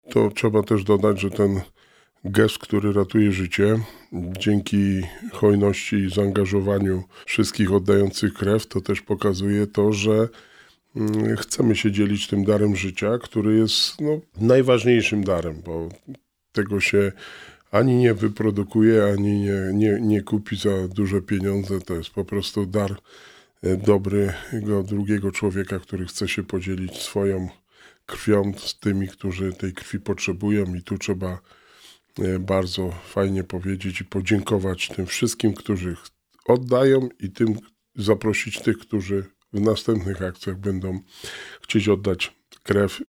W studiu Radia Rodzina gościł Wojciech Błoński, wójt Gminy Długołęka.